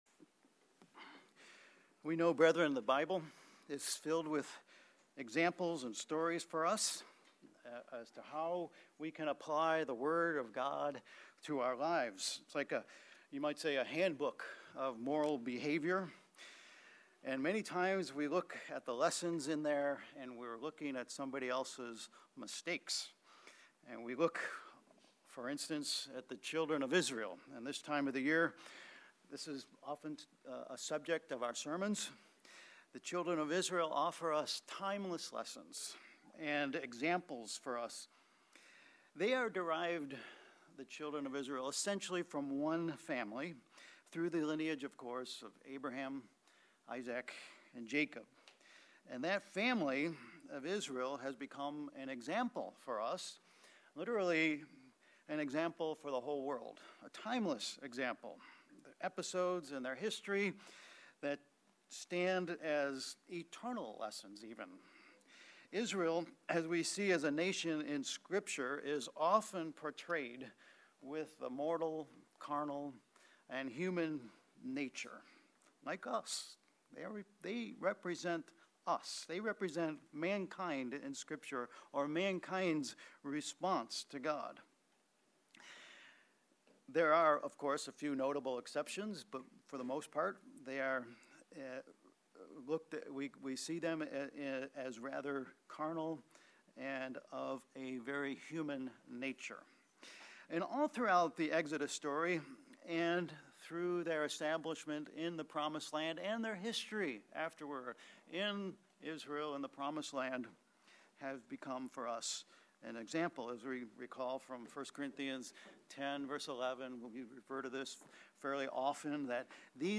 Sermons
Given in Southern New Hampshire Worcester, MA